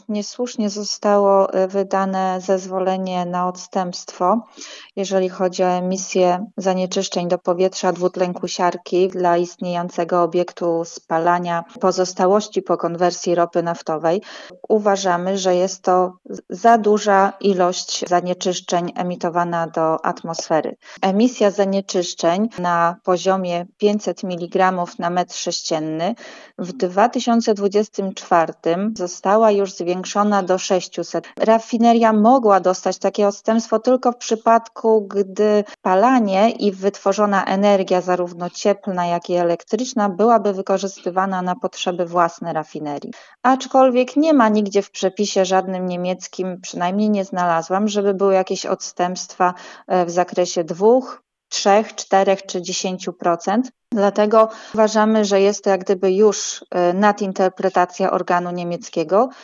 W rozmowie nie kryje oburzenia: